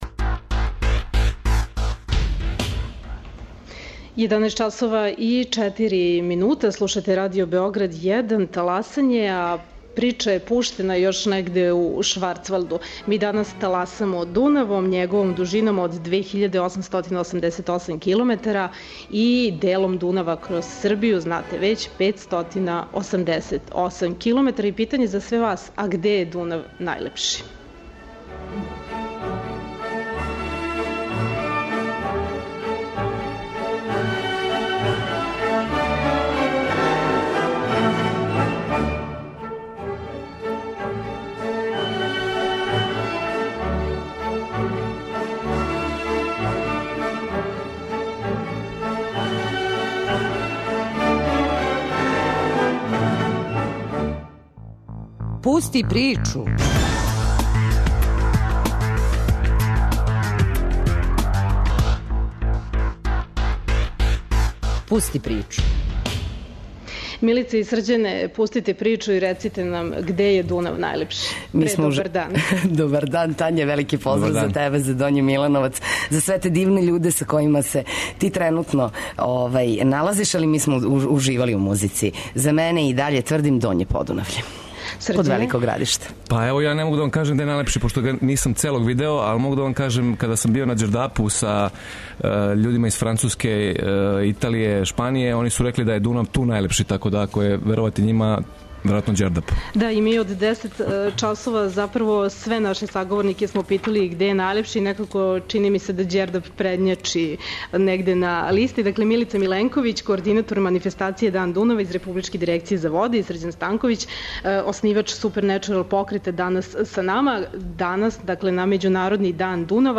На Међународни дан Дунава, који се прославља од 2004. године, "Таласање" емитујемо са обале Дунава, из дела у ком је на свом току, по многима, Дунав најлепши. Наши саговорници биће представници Националног парка Ђердап, туристичких организација Кладова и Голупца, републичке Дирекције за воде, дунавски хроничари и многи дурги, заљубљеници у Дунав.